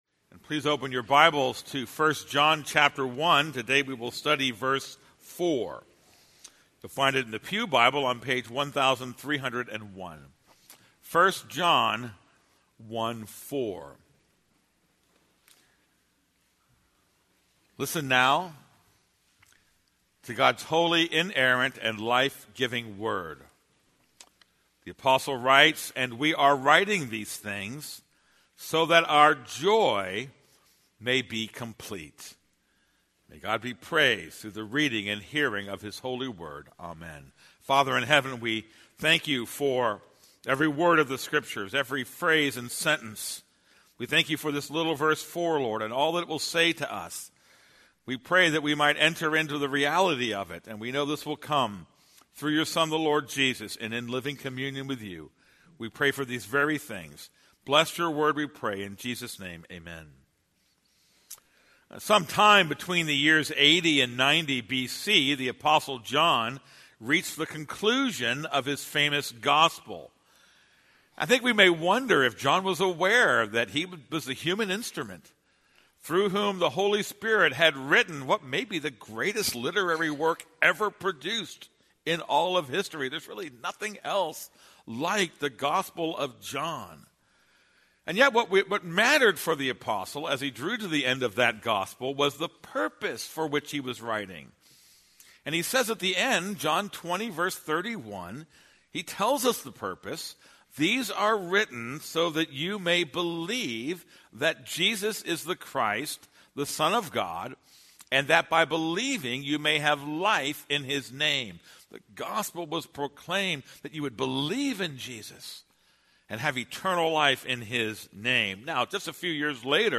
This is a sermon on 1 John 1:4.